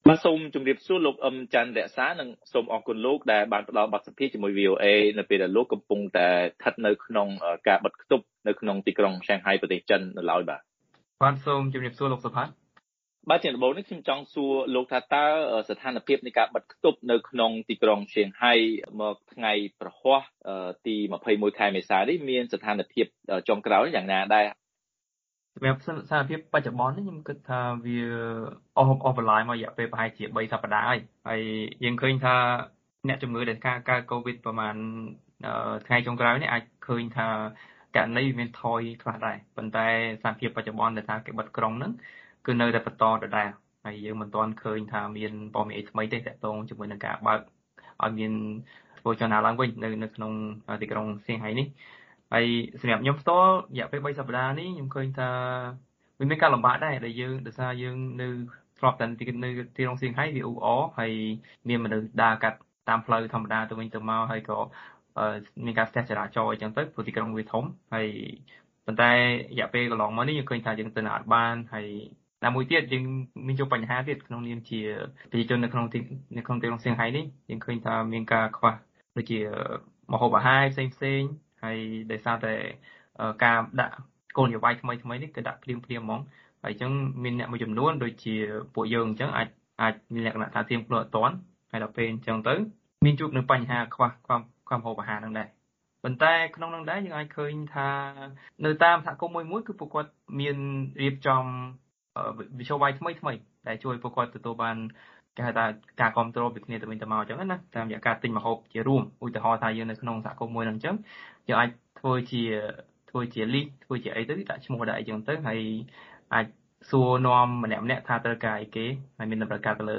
បទសម្ភាសន៍ VOA៖ យុវជនខ្មែរនៅក្រុងសៀងហៃថាស្ថានភាពបិទខ្ទប់ដោយសារកូវីដបានធូរស្រាលបន្តិច